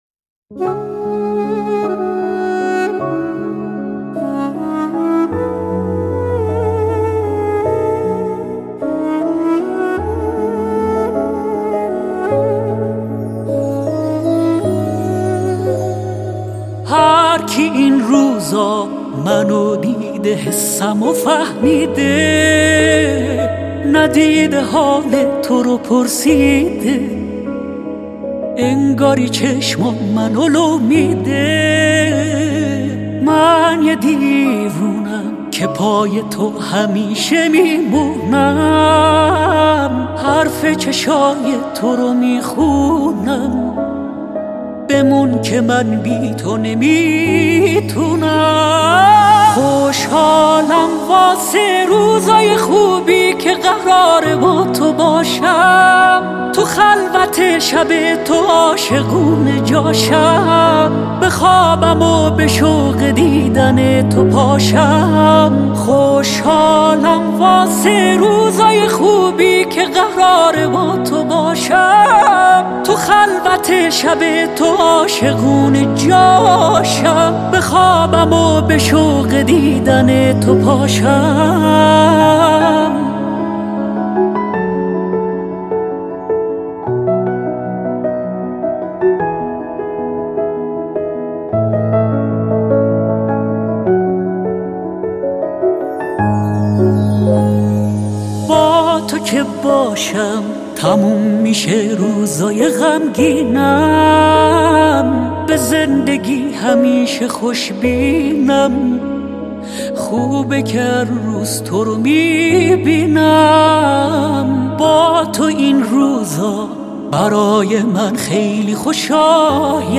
موضوعات: تک آهنگ, دانلود آهنگ پاپ